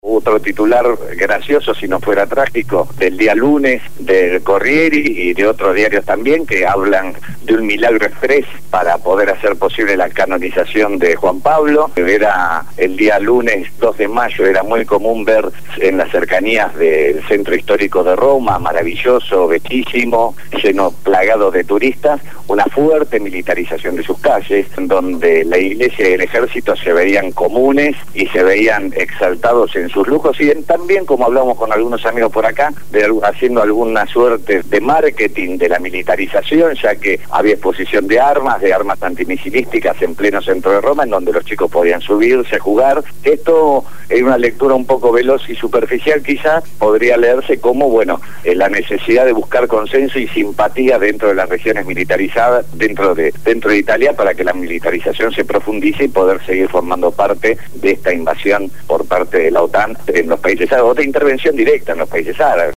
habló desde Italia y dio un completo informe de la situación crítica que vive el país europeo.